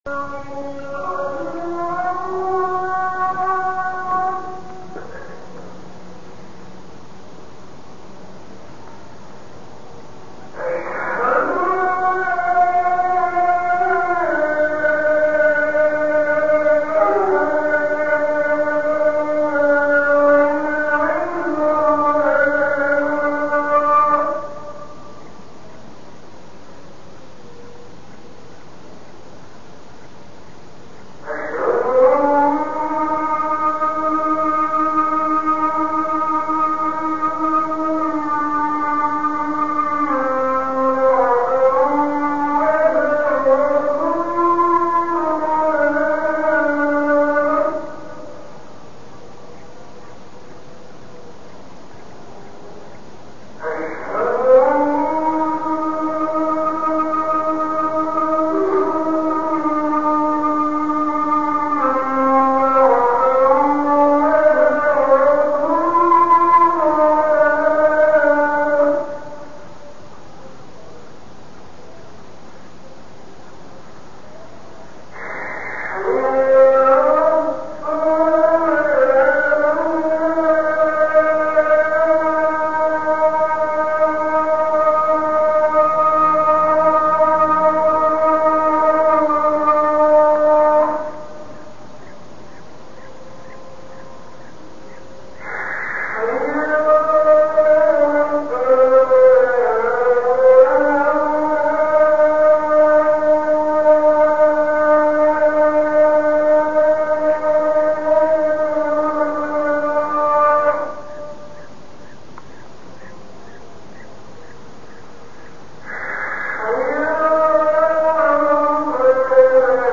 I recorded a Muslim guy praying at about 5 in the morning. I stuck my recorder out the window of my room at Hotel Govardhan and snagged some of his amplified magic.
There is a short version, with one brain-splitting burst and a longer one that goes on for about 2 1/2 minutes.
morning_muslim.8.mp3